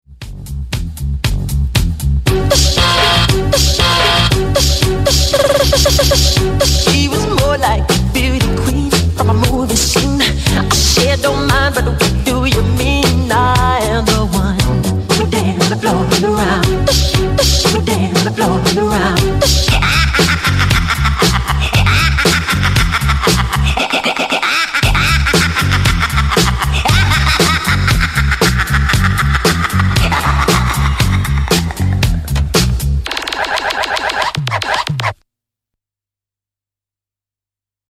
ディスコ／オールド・スクール・ファン、そしてメガ・ミックスからカットアップ、